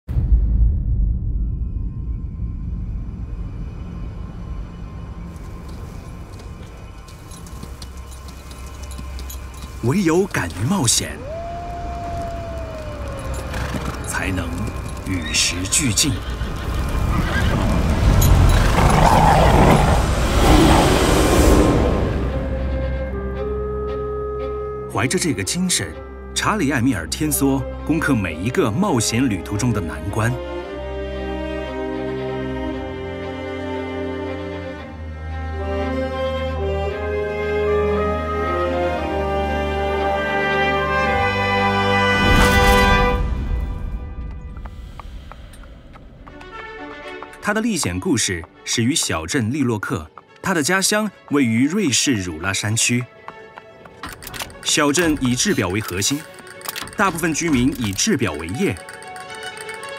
Commercial, Young, Natural, Friendly, Warm
Corporate